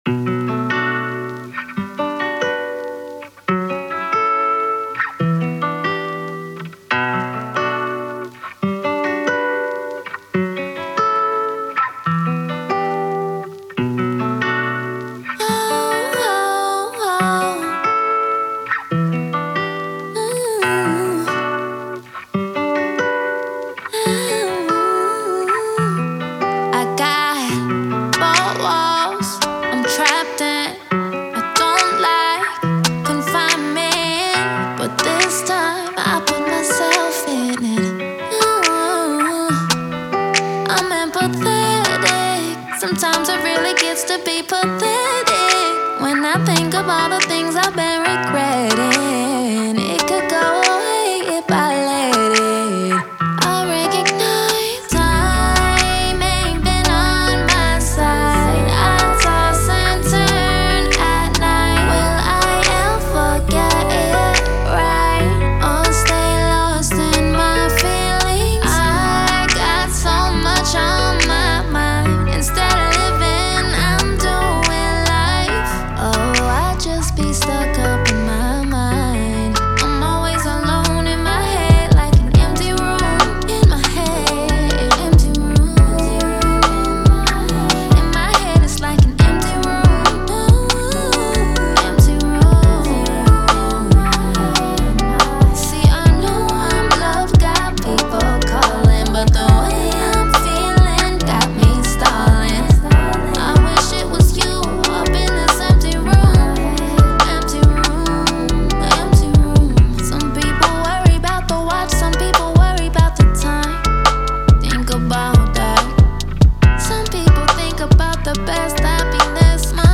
R&B
B Minor